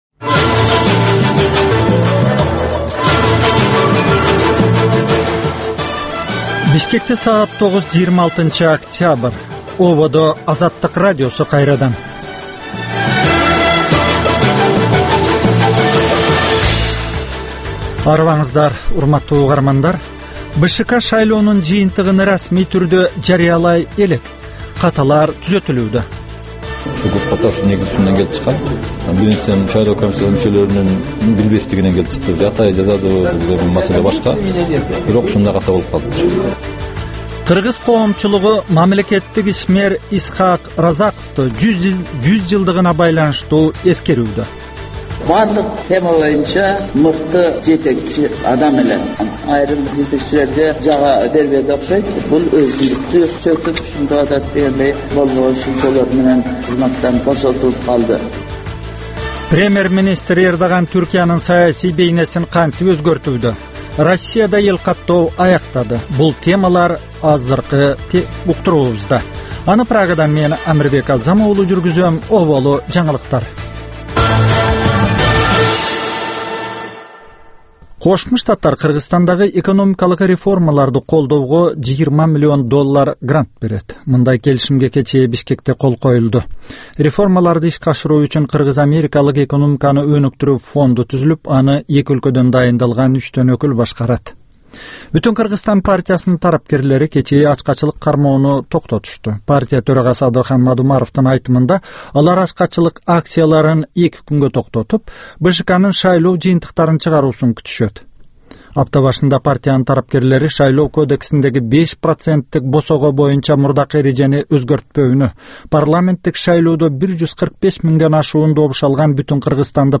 Таңкы 9дагы кабарлар